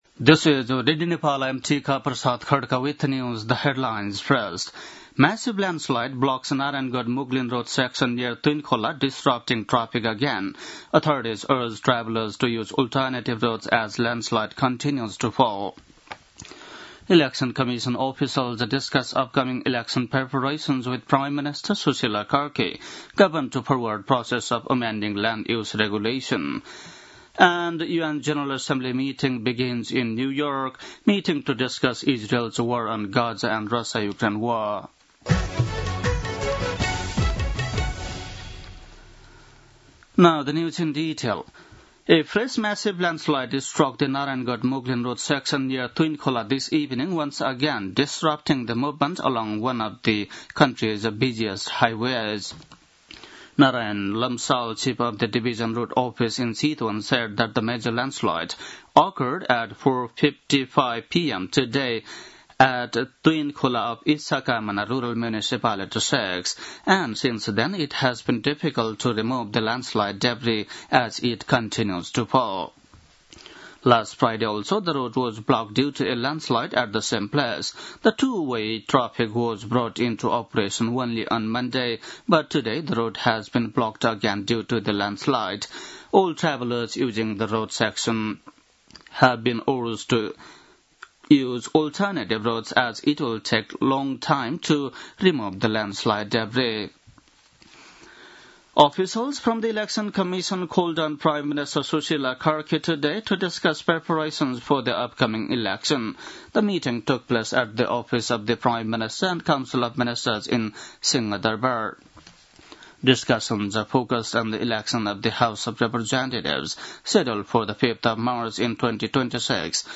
बेलुकी ८ बजेको अङ्ग्रेजी समाचार : ७ असोज , २०८२